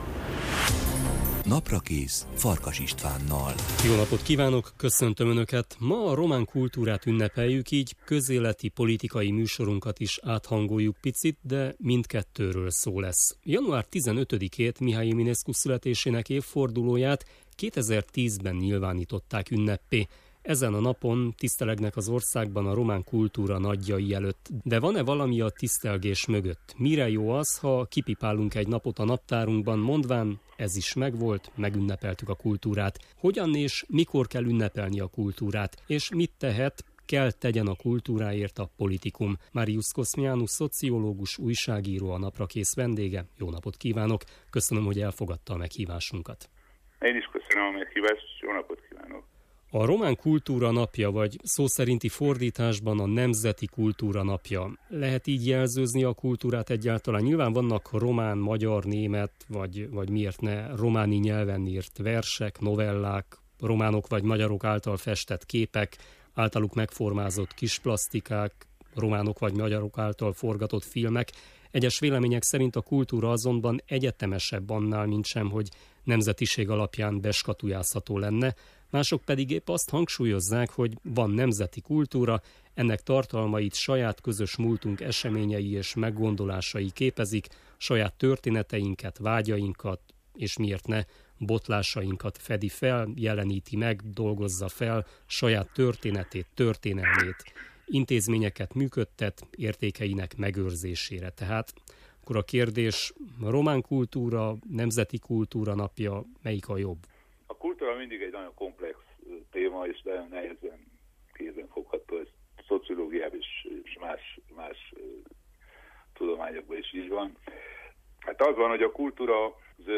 újságíróval beszélgettünk a román kultúra napján